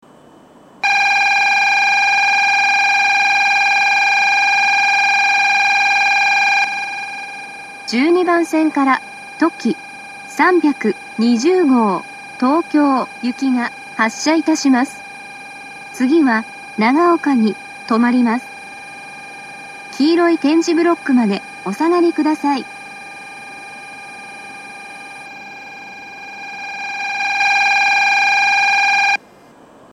２０２１年７月中旬にはCOSMOS連動の放送が更新され、HOYA製の合成音声による放送になっています。
１２番線発車ベル とき３２０号東京行の放送です。